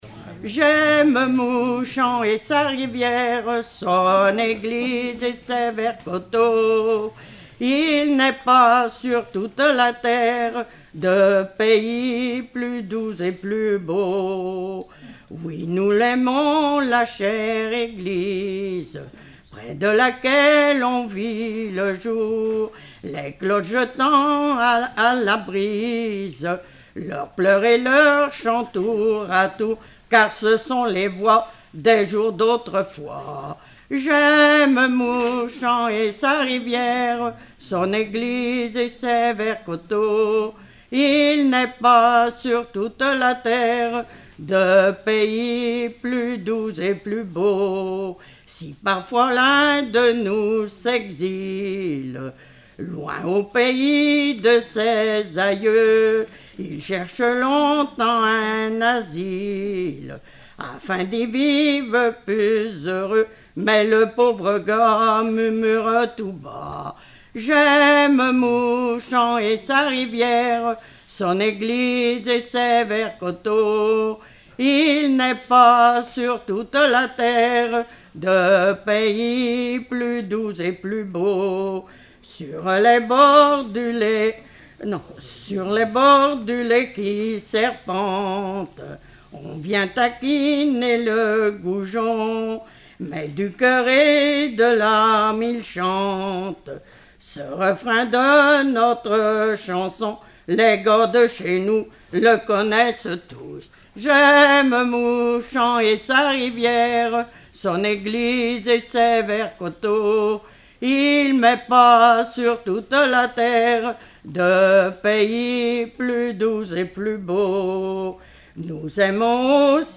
Répertoire de chansons populaires et traditionnelles
Pièce musicale inédite